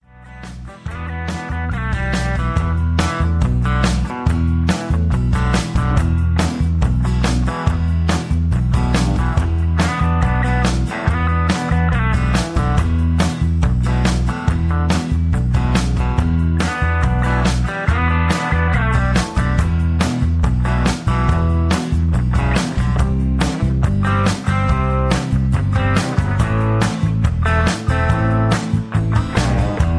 backing tracks
rock and roll, country rock, southern rock